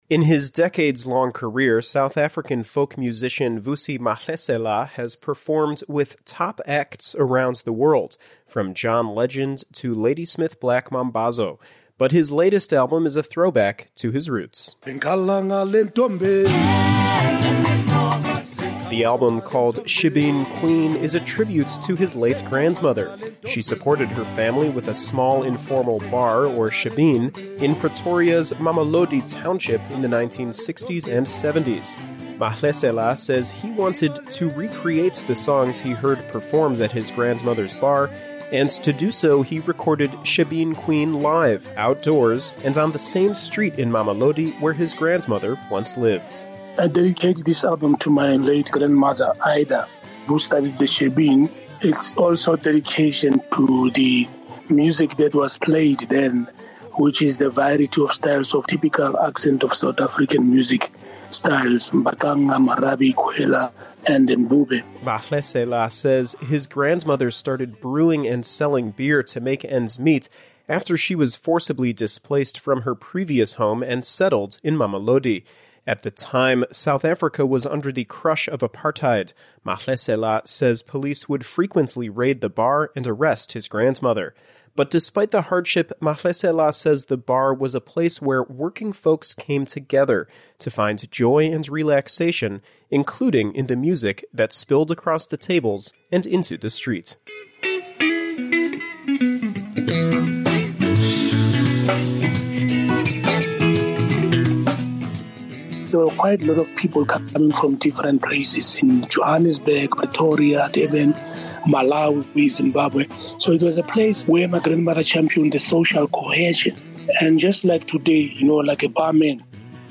South African folk musician Vusi Mahlasela says he hopes his latest album can lift people's spirits during the COVID-19 pandemic.